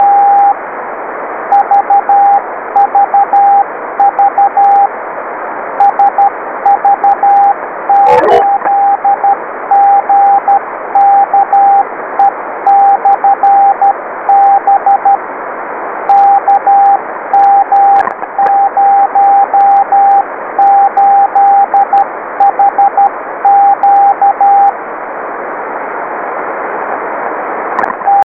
REGISTRAZIONI DEGLI ECHI LUNARI DEI BEACONS E DEI QSO